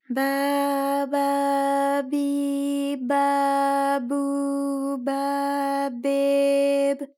ALYS-DB-001-JPN - First Japanese UTAU vocal library of ALYS.
ba_ba_bi_ba_bu_ba_be_b.wav